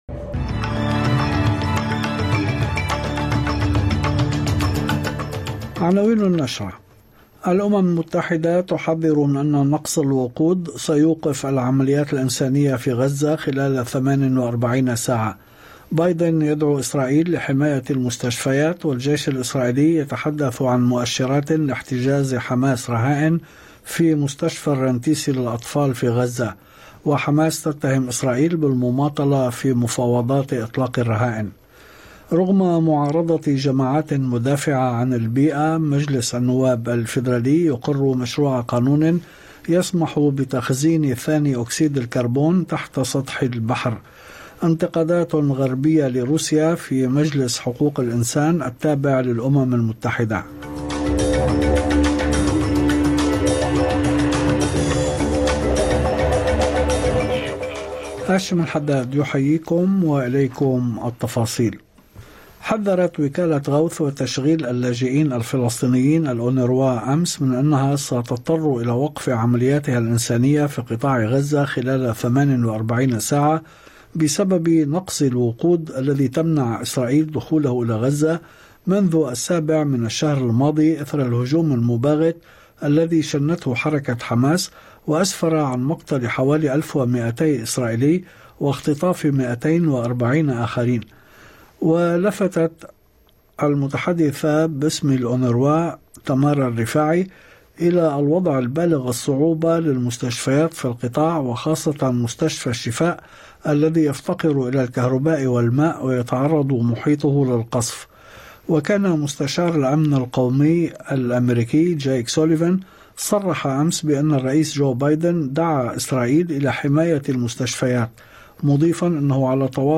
نشرة أخبار المساء 14/11/2023